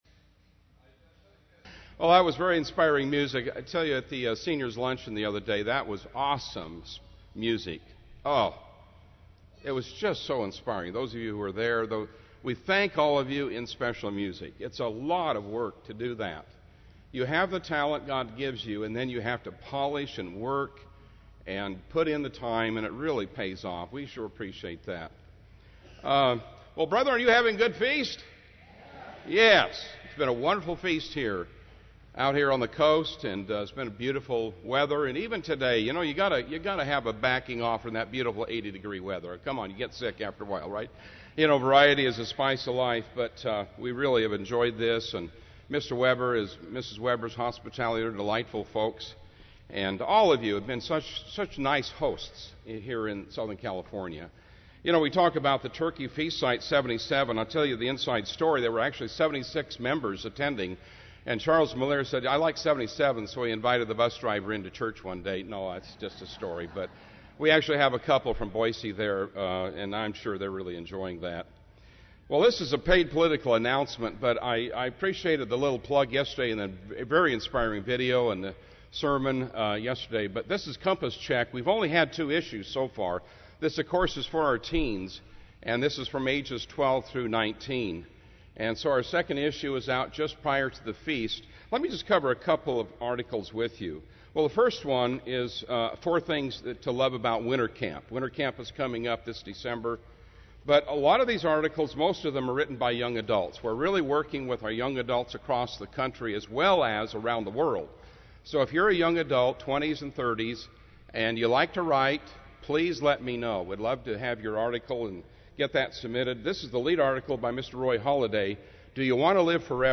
This sermon was given at the Oceanside, California 2015 Feast site.